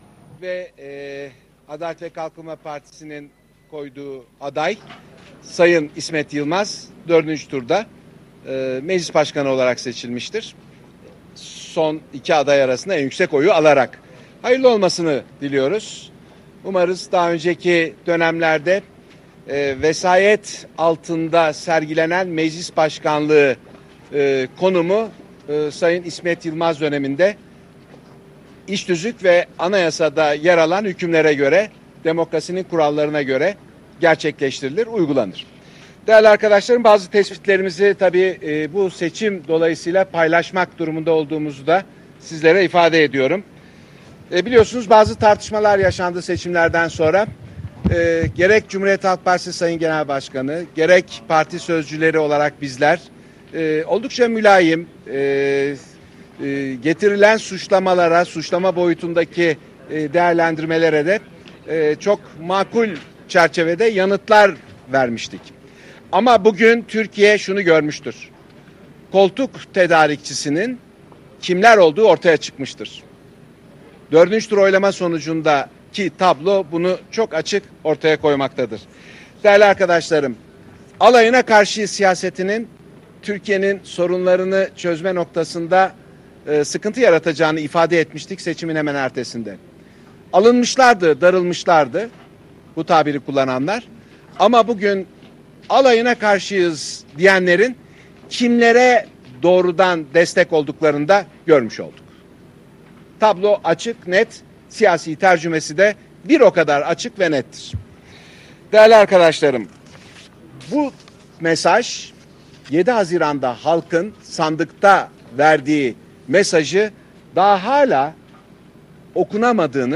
Haluk Koç'un Açıklamaları